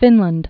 (fĭnlənd)